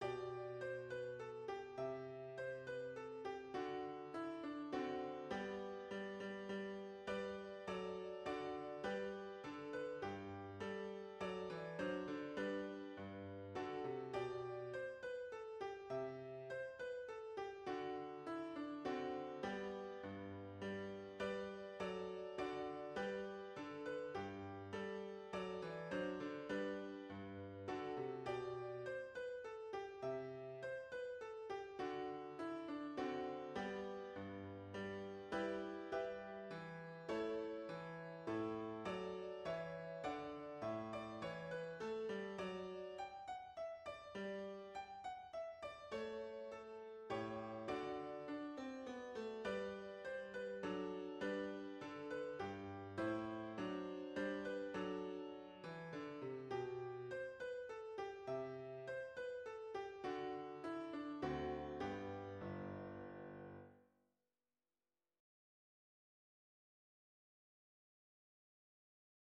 String Practice Pieces
Violin Solo